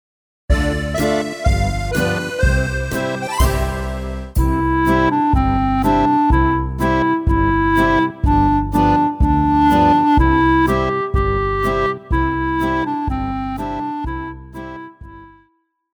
降B調单簧管
乐团
童谣,经典曲目,传统歌曲／民谣
演奏曲
独奏与伴奏
有主奏
有节拍器